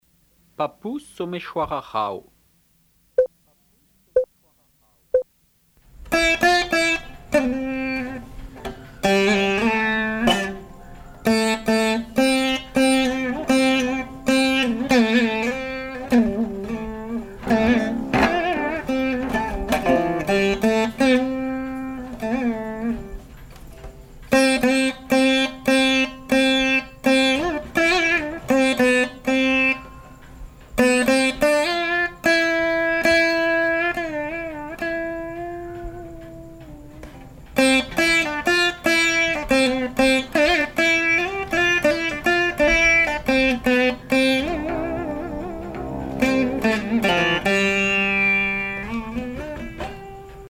Alap, tänam
Musique carnatique
Pièce musicale inédite